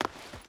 Stone Run 3.wav